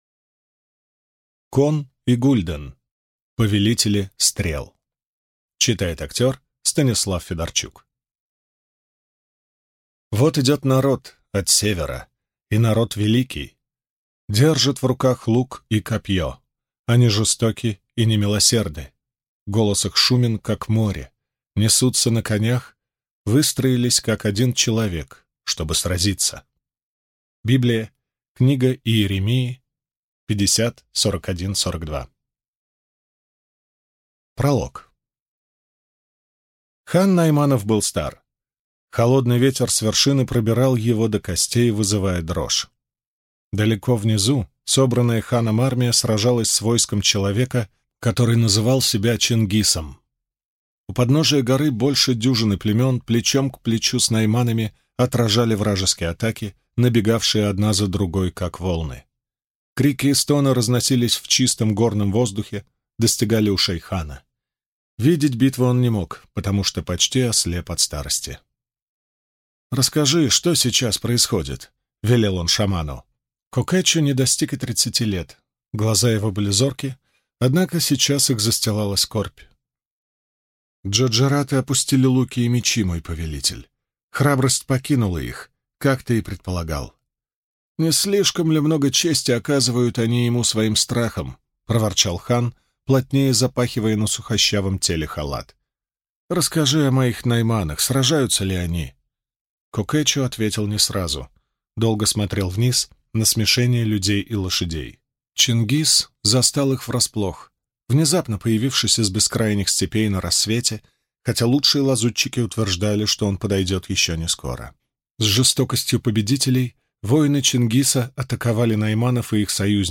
Аудиокнига Повелители стрел | Библиотека аудиокниг
Прослушать и бесплатно скачать фрагмент аудиокниги